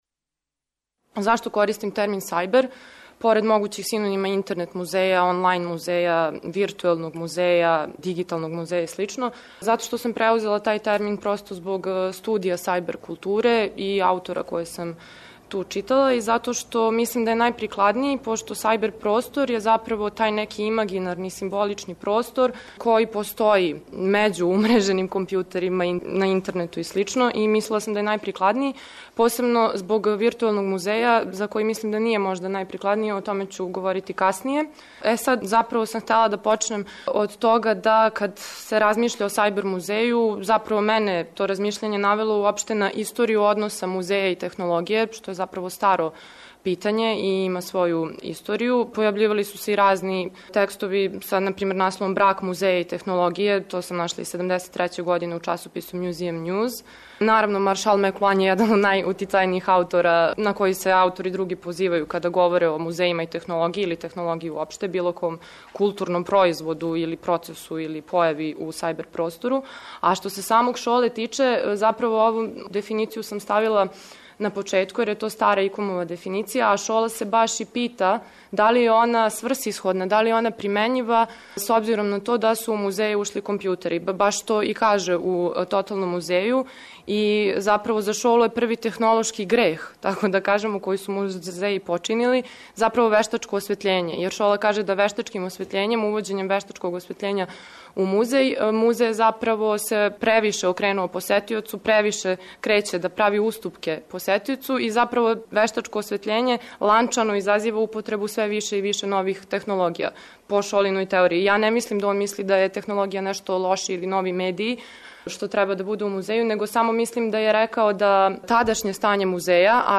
Трибине
преузми : 9.55 MB Трибине и Научни скупови Autor: Редакција Преносимо излагања са научних конференција и трибина.